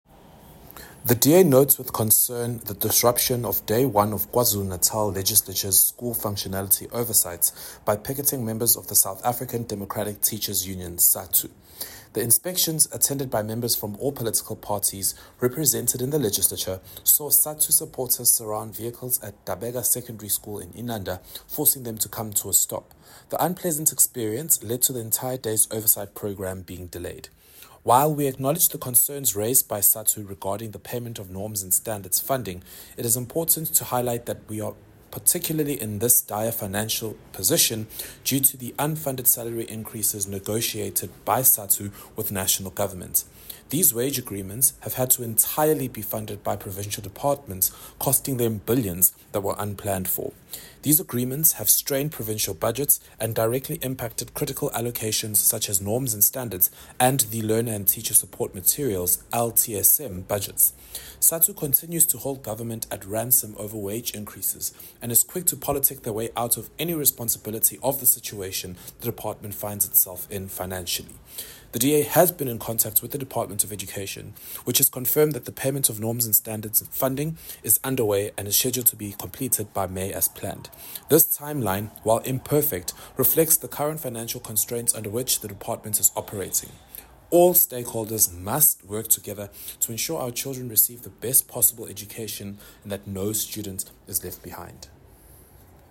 Note to Editors: Please note Sakhile Mngadi, MPL sound bites in